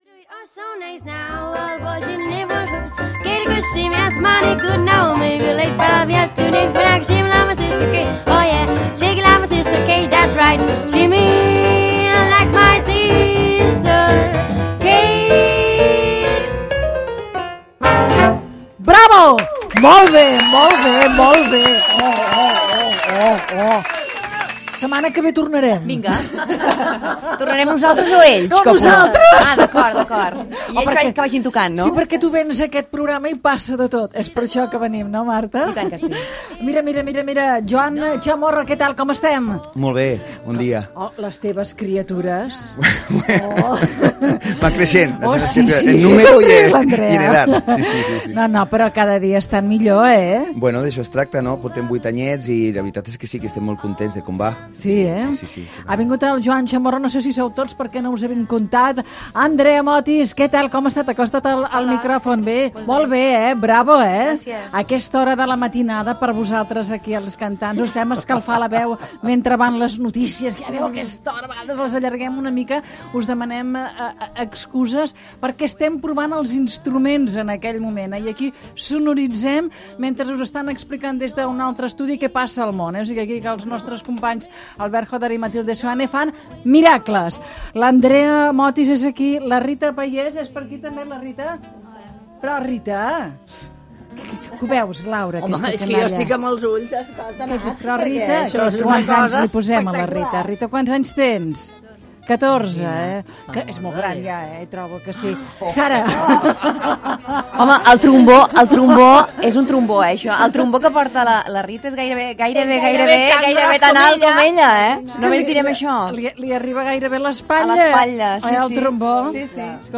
Tema tocat en directe per la Sant Andreu Jazz Band.
Segon tema tocat en directe.